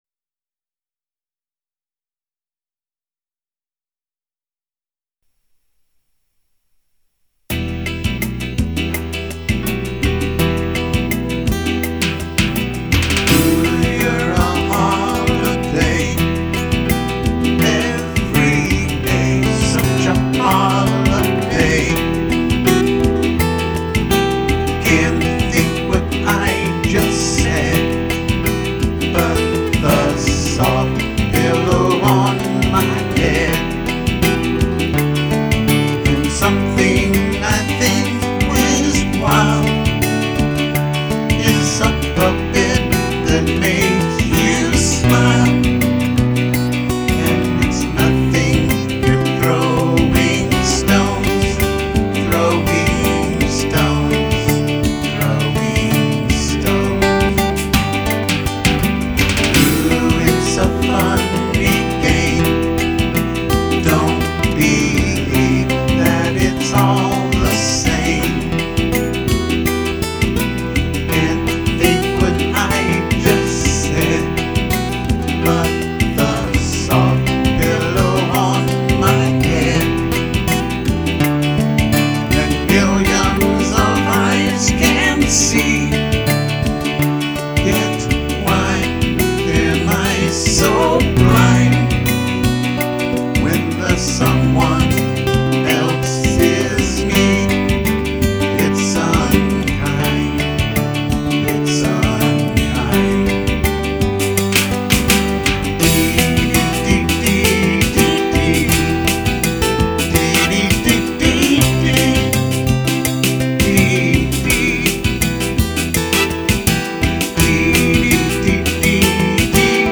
AT THE STATE FAIR OF TEXAS